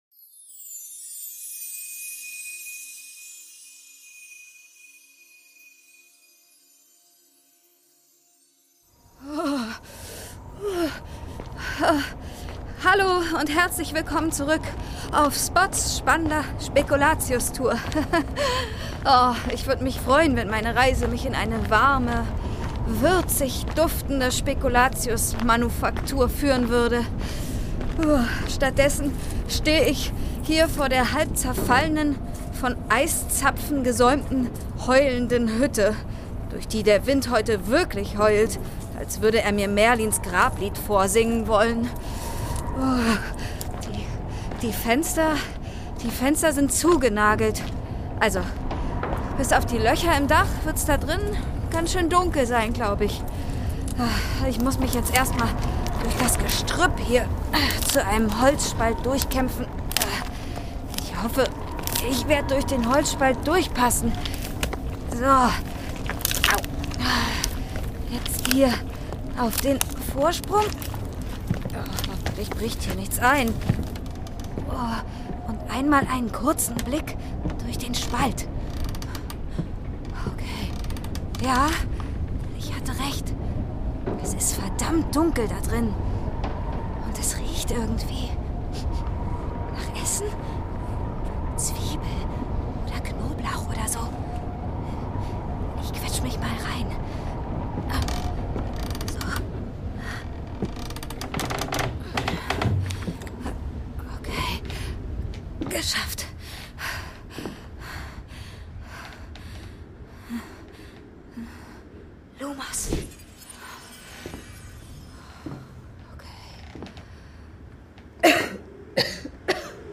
22. Türchen | Begegnung mit einem Vampir - Eberkopf Adventskalender ~ Geschichten aus dem Eberkopf - Ein Harry Potter Hörspiel-Podcast Podcast